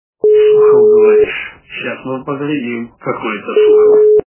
» Звуки » Из фильмов и телепередач » Белое солнце пустыни - Сухов, говоришь...